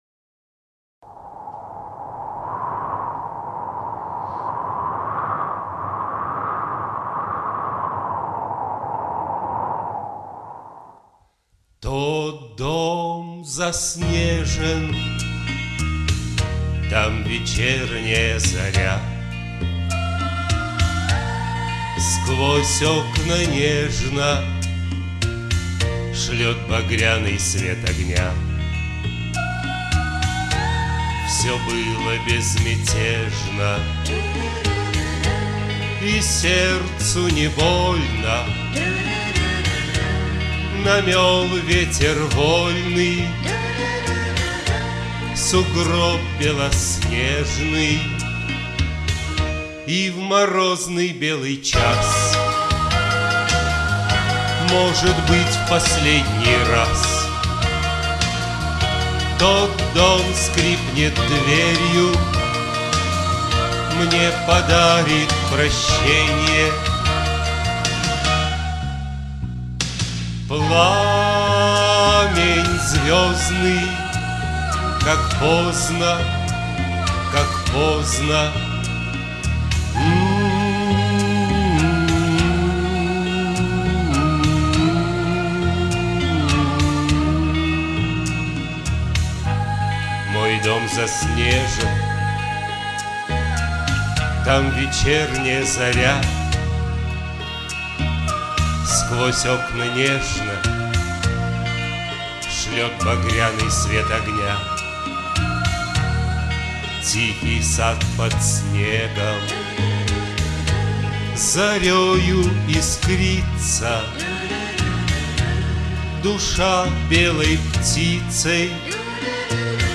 Бэк-вокал...
Очень приятное звучание!